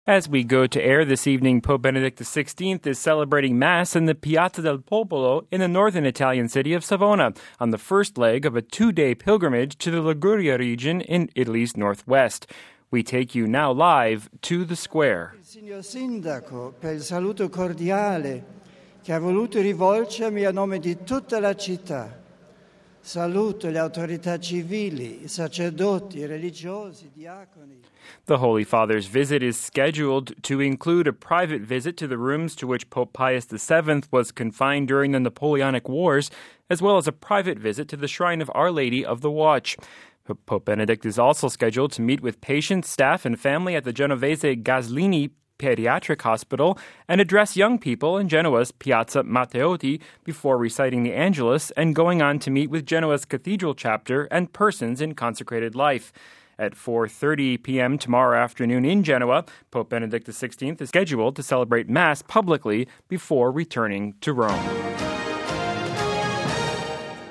Pope Benedict XVI Celebrates Mass in Savona
We take you now live to the Square…